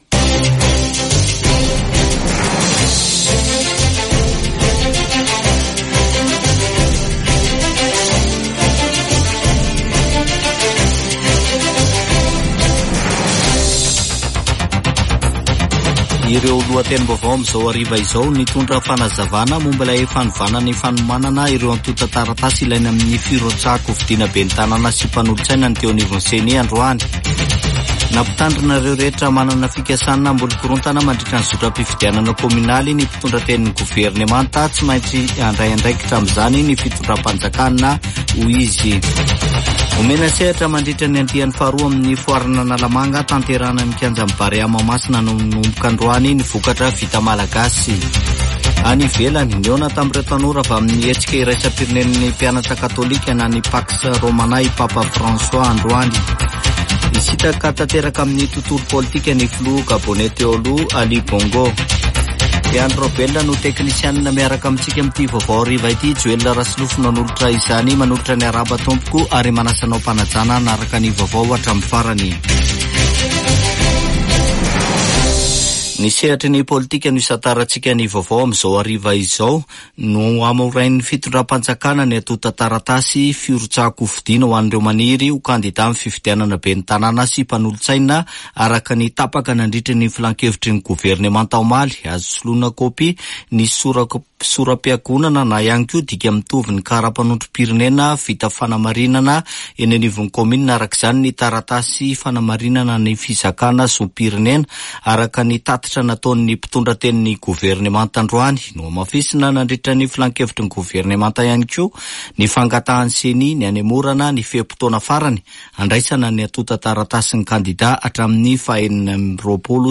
[Vaovao hariva] Zoma 20 septambra 2024